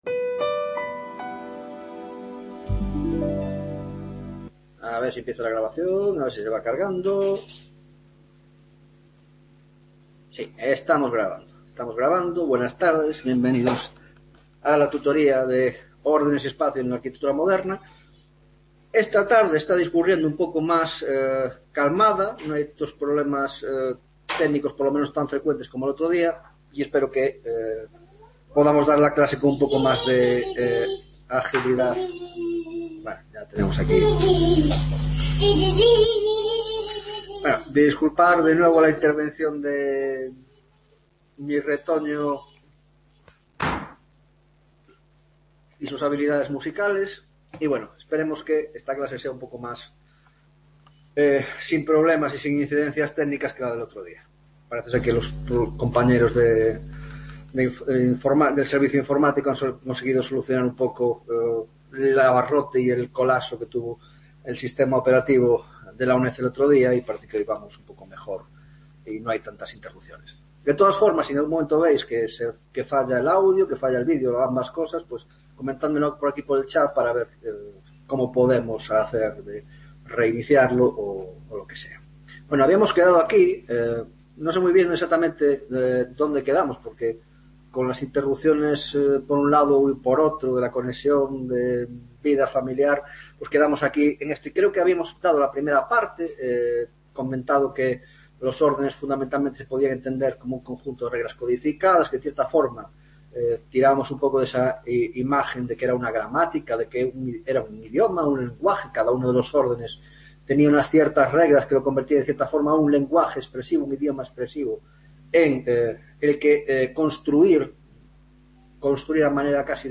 5ª Tutoria de Órdenes y Espacio en la Arquitectura Moderna - Teoria de los órdenes en Italia (2ª parte)